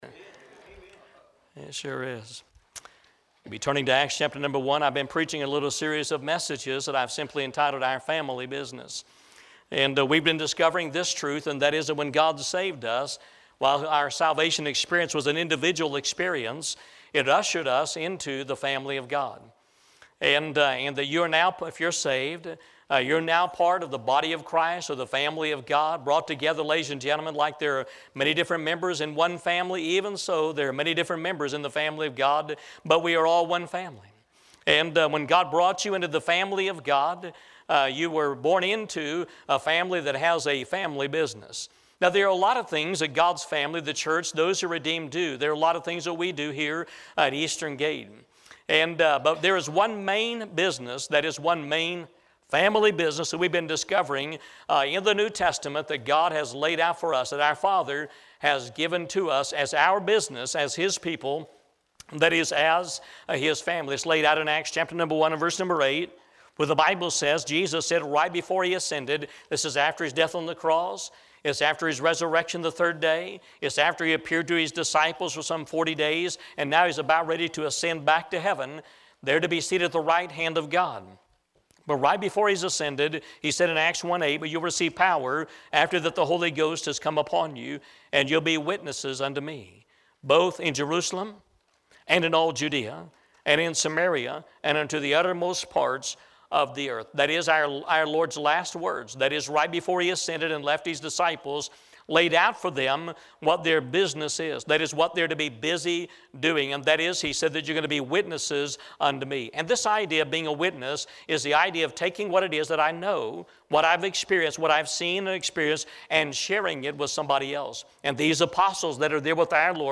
The Sermon